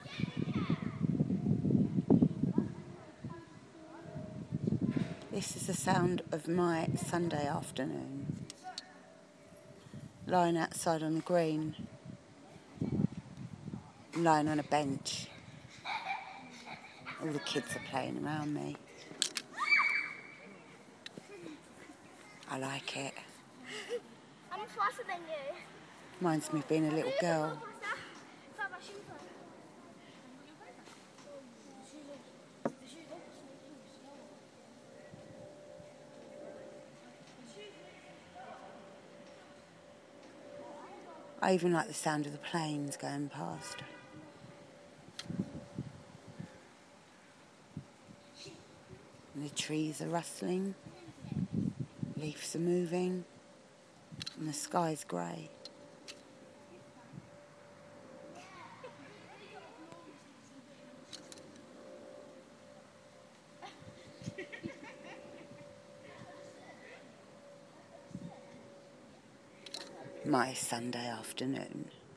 My Sunday afternoon, kids playing, planes passing over and the wind in the trees